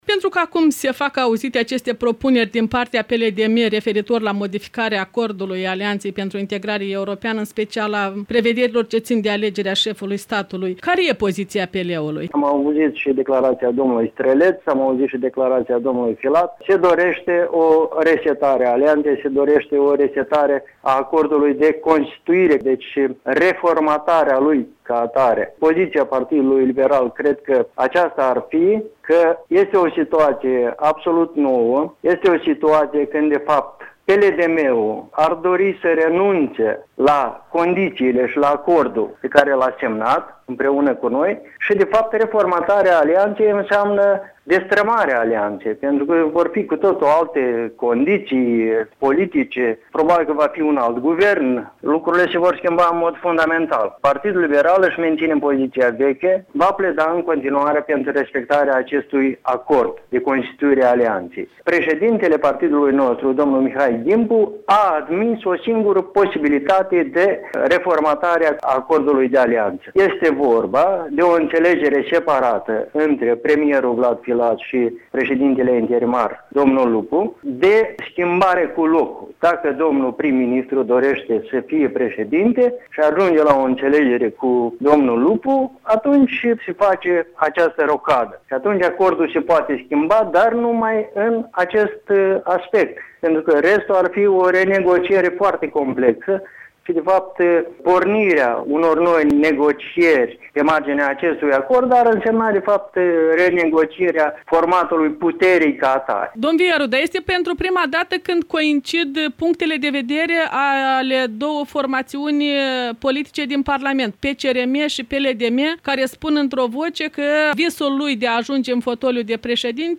Un interviu cu vicepreşedintele PL, Boris Vieru